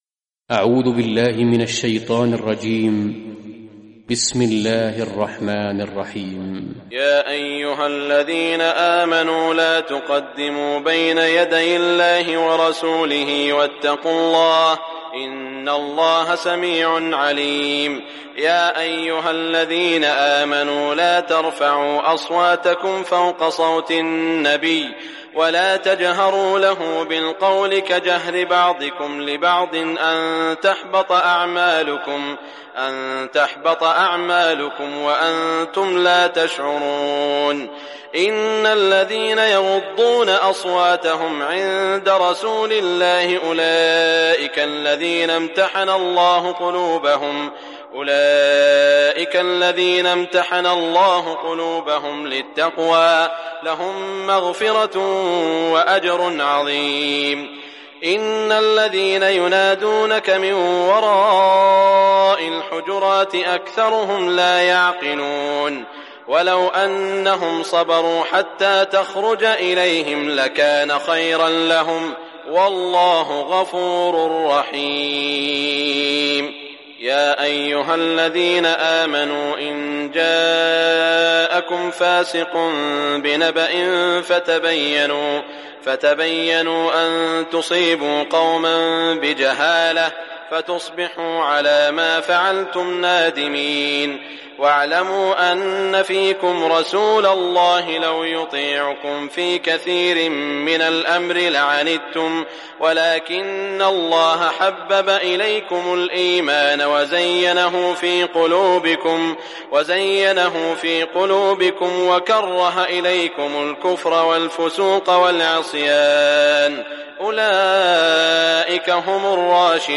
Listen or play online beautiful arabic recitation of Surah Al-Hujurat by Imam e Kaaba Sheikh Saud al-Shuraim.